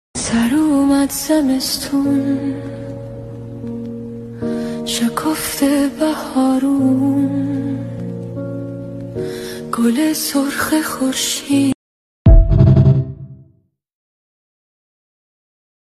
ژانر: پاپ
🎤 خواننده : صدای زن